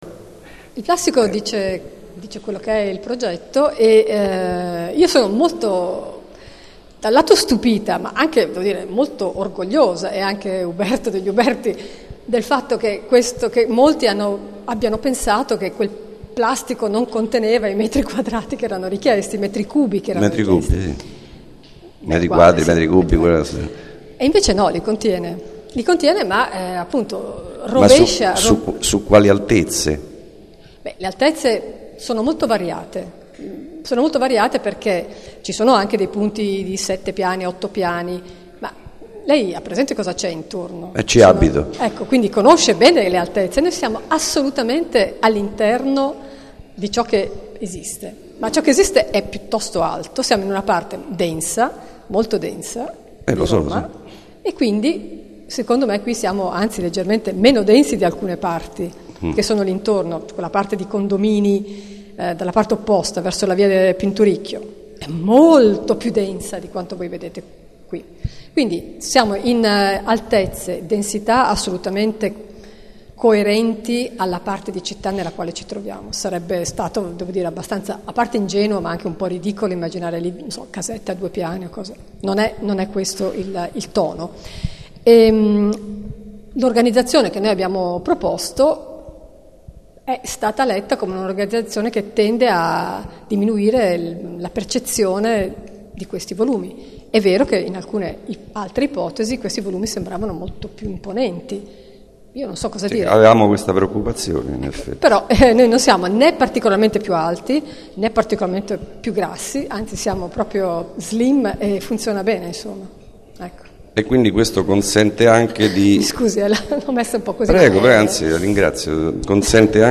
Piano di recupero del Quartiere Città della Scienza - Ascolto audio dell'incontro